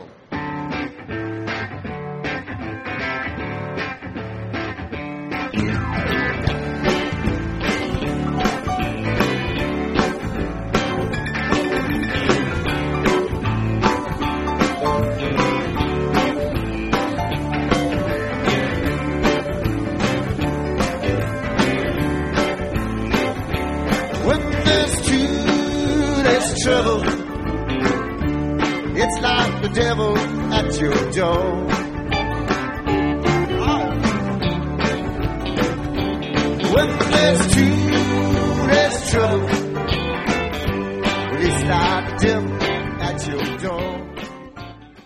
lead guitar & vocals
bass, slide guitar, & vocals
drums
vocals & cowbell
organ, piano & vocals
Jamband
Psychedelic
Rock